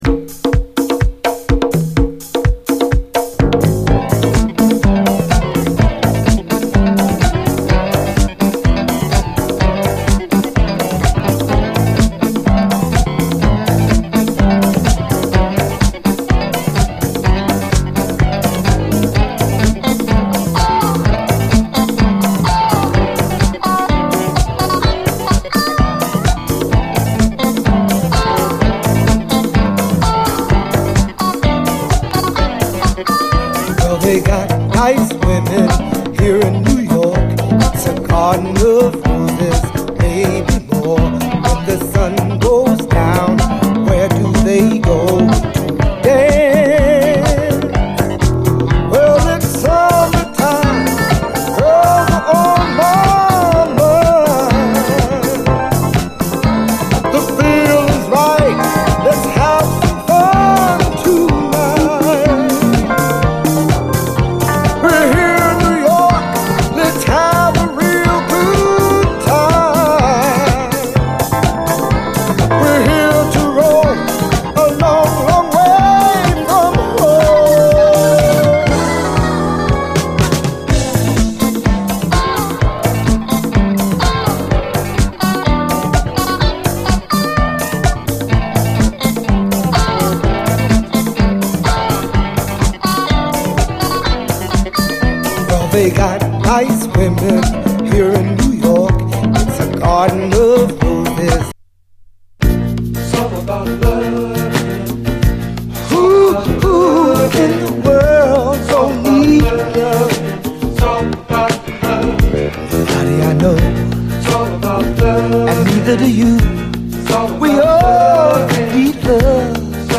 SOUL, 70's～ SOUL, DISCO, LATIN
特徴あるギター・フレーズのトロピカル・ディスコ
ラテン・ロック経由のラテン・ディスコ・アルバム！
レイドバックしたムードが沁みるメロウ・ラテン・ロック